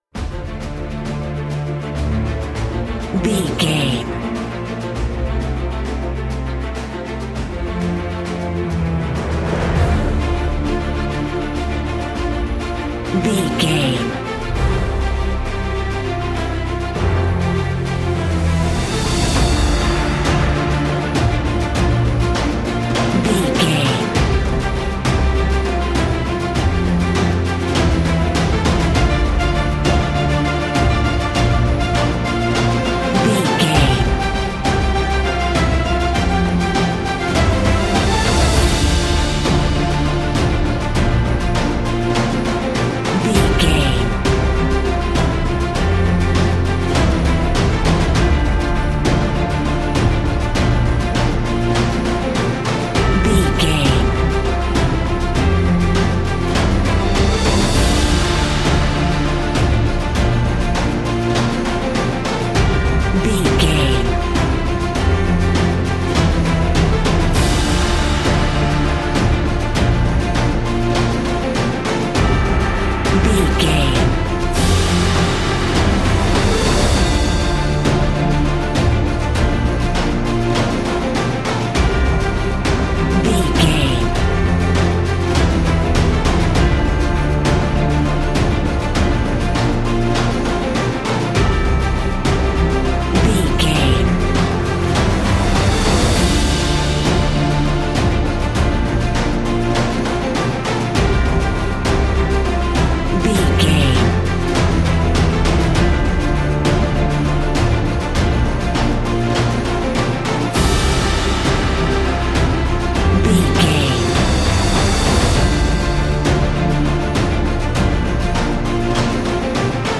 Epic / Action
Uplifting
Aeolian/Minor
energetic
powerful
brass
cello
drums
strings